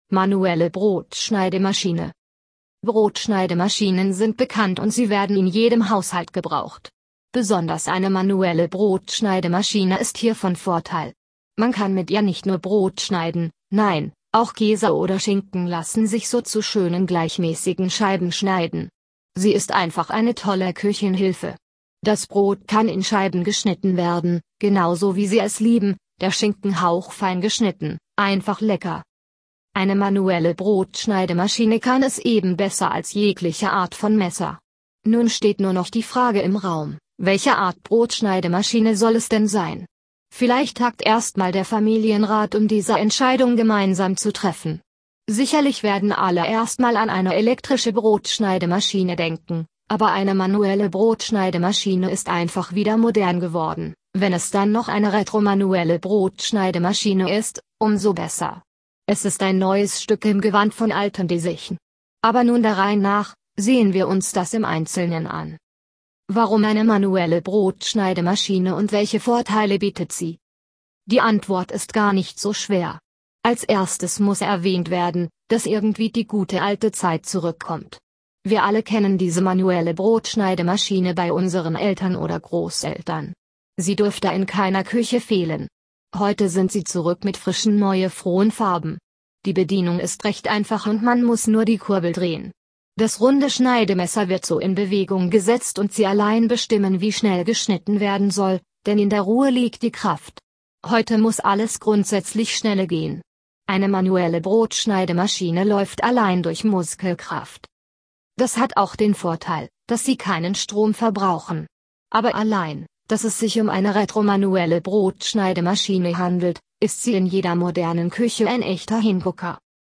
(Audio für Menschen mit Seh- oder Leseschwäche – Wir lesen Ihnen unseren Inhalt vor!)